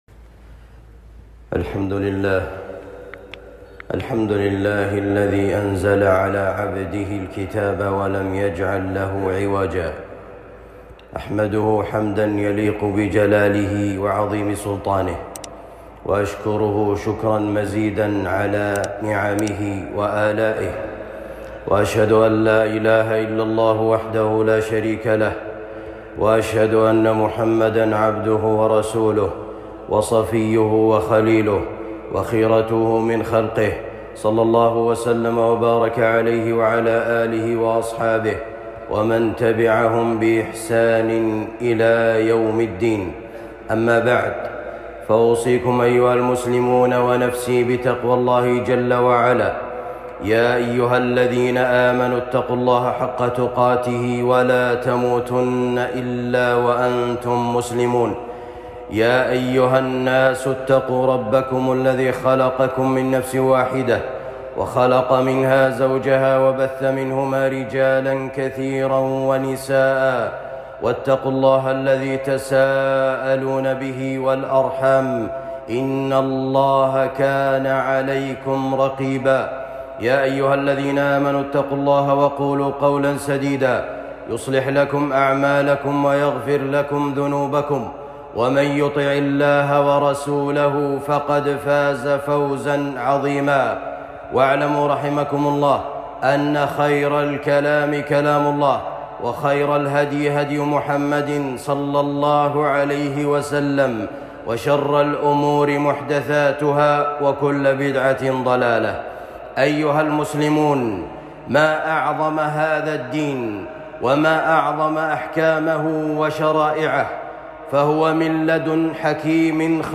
تنفيذ الأحكام على أهل المحاربة والفساد خطبة جمعة